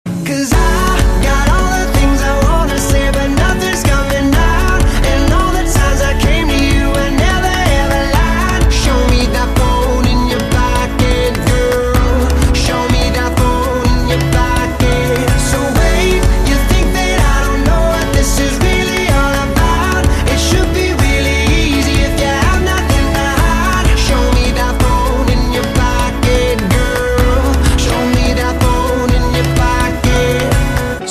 M4R铃声, MP3铃声, 欧美歌曲 55 首发日期：2018-05-15 03:37 星期二